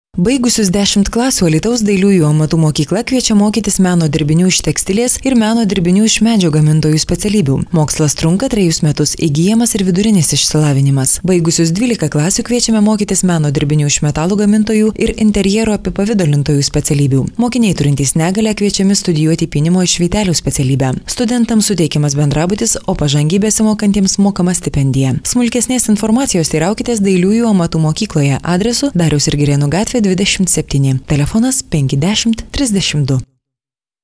Sprechprobe: Werbung (Muttersprache):
lithuanian female voice over talent